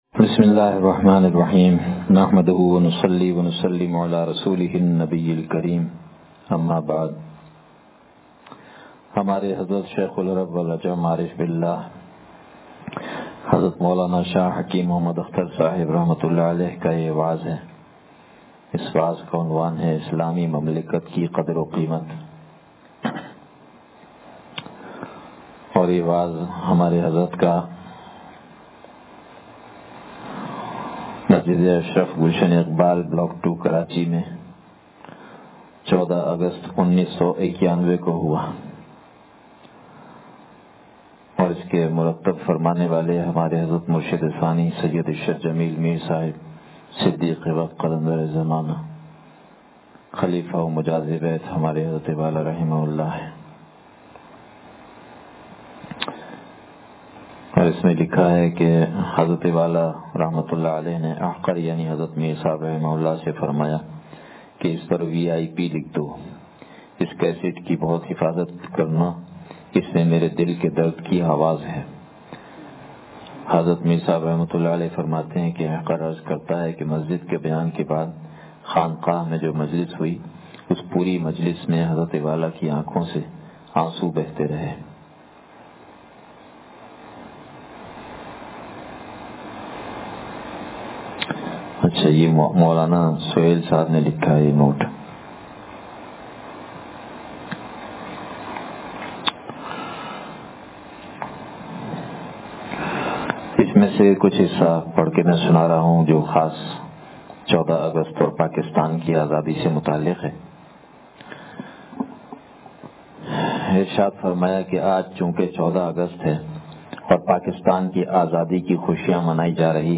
اسلامی مملکت کی قدر و قیمت سے بیان